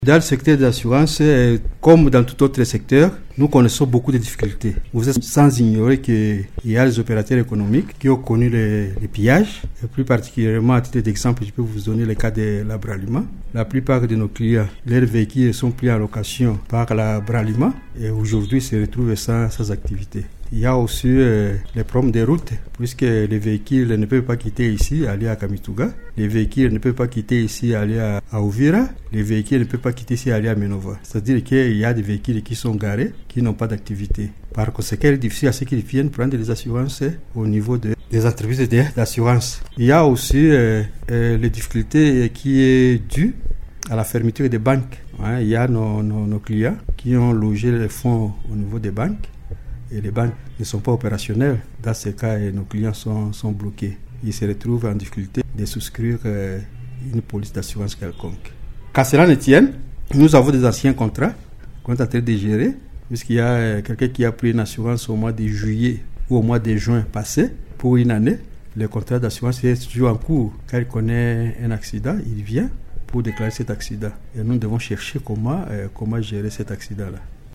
dans un entretien accordé à Radio Maendeleo vendredi 23 Mai.